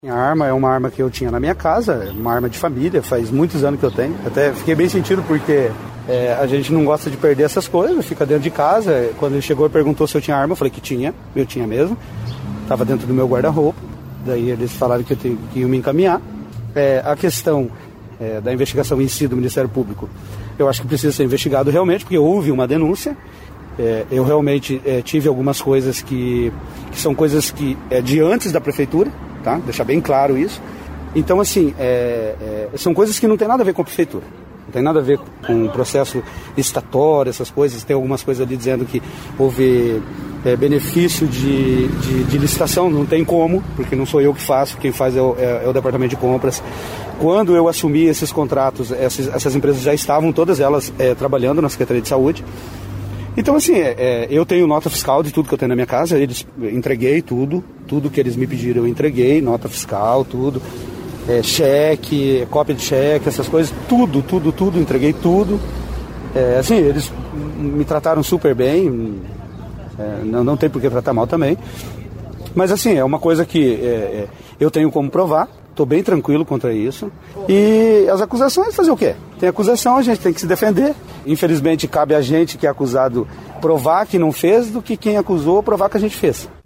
Em entrevista à CBN Cascavel nesta terça-feira(02), o vereador do PDT, Fernando Halberg, falou das denúncias de irregularidades nas reformas de postos de saúde em Cascavel. O vereador havia denunciado no ano passado, possíveis fraudes na fiscalização e execução de contratos de prestação de serviços de reforma e manutenção nas unidades de saúde do Município.